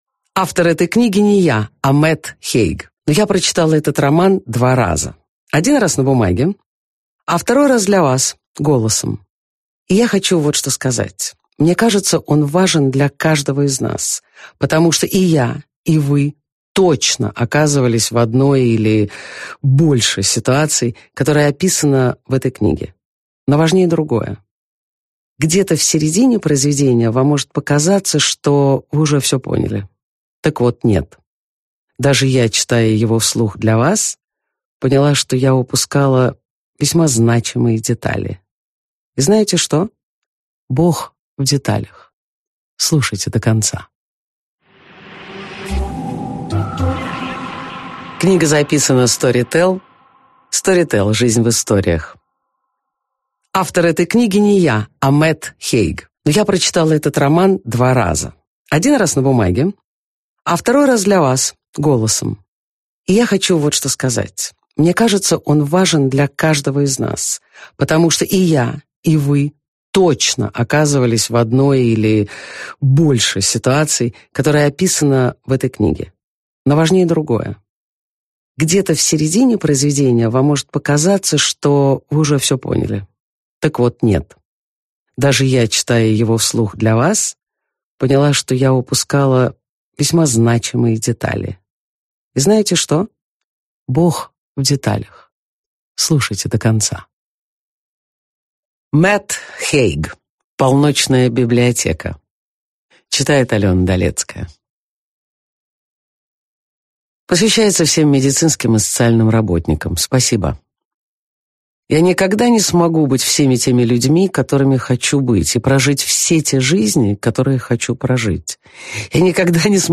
Аудиокнига Полночная библиотека | Библиотека аудиокниг